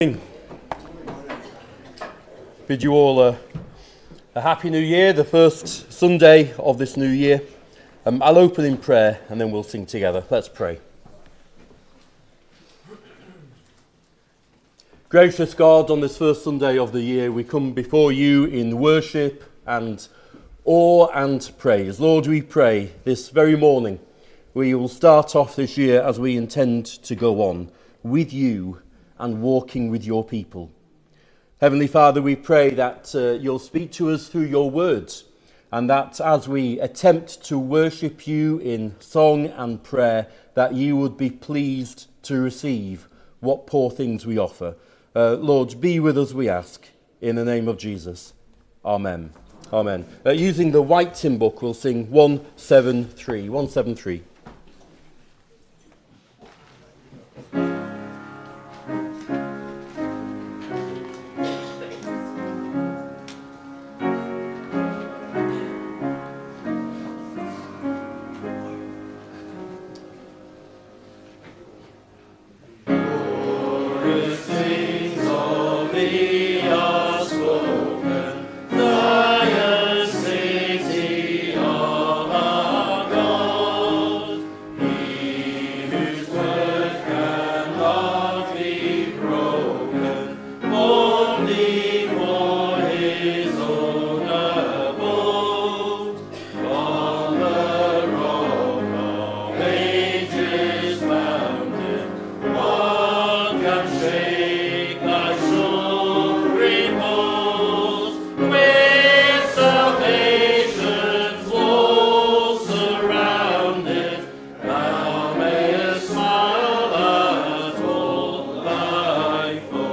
Below is audio of the full service.
2026-01-04 Morning Worship If you listen to the whole service on here (as opposed to just the sermon), would you let us know?